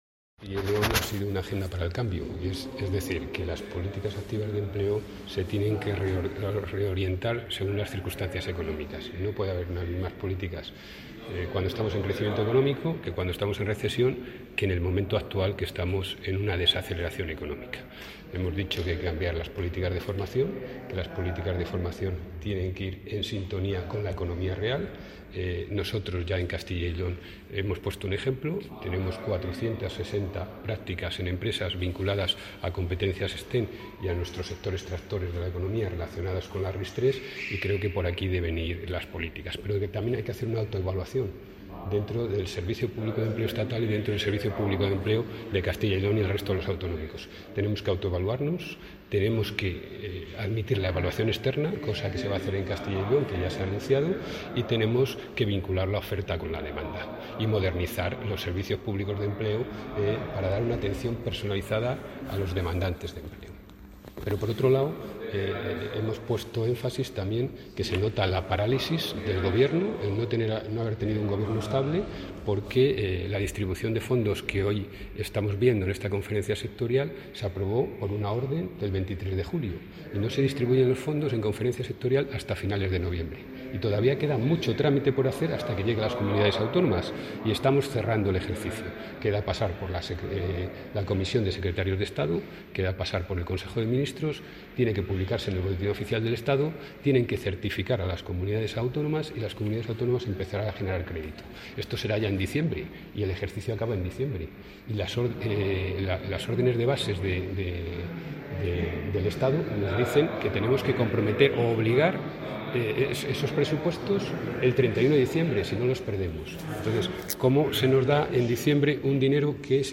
Intervención del consejero.